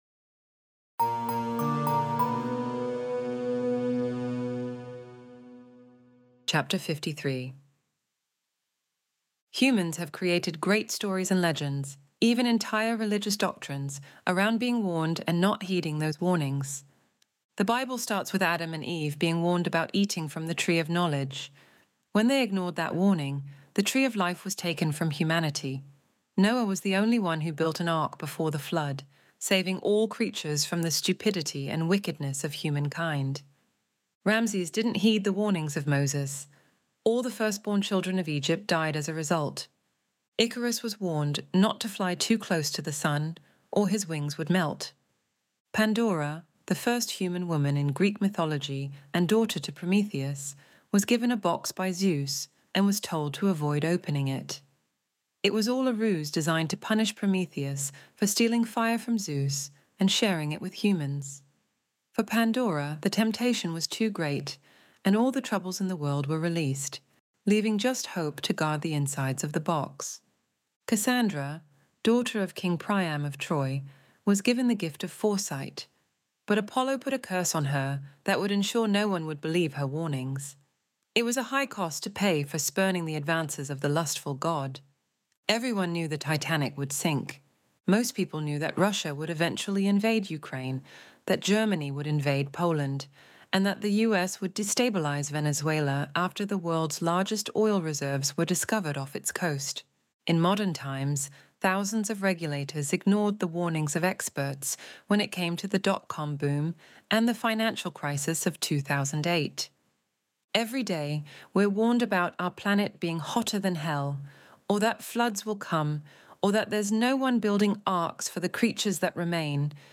Extinction Event Audiobook Chapter 53